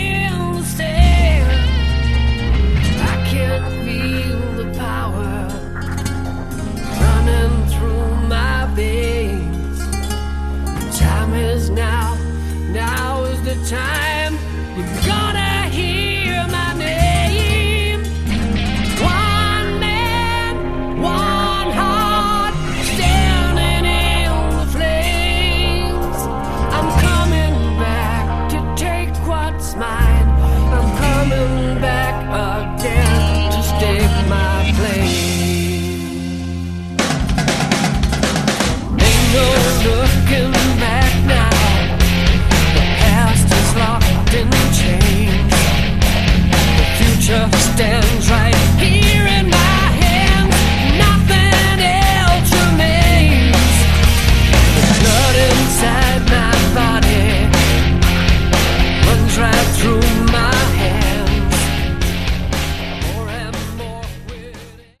Category: AOR